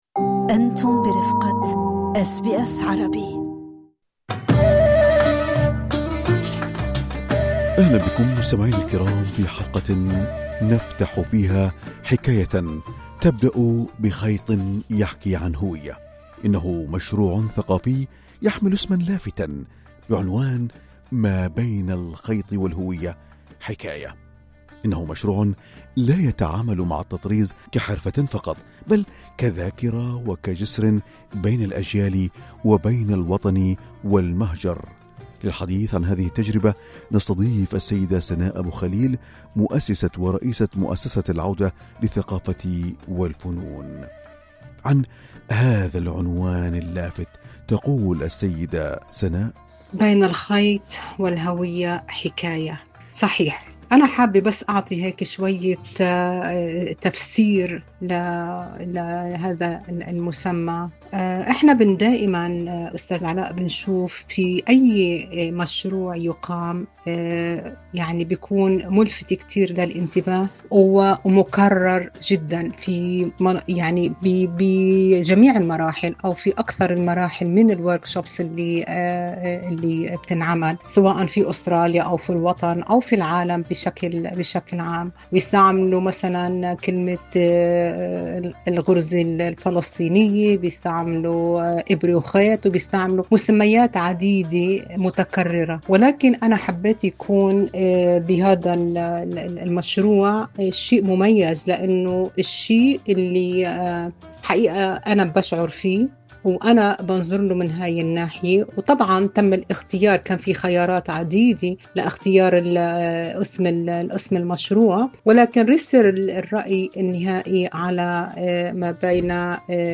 للاستماع لتفاصيل اللقاء، اضغطوا على زر الصوت في الأعلى.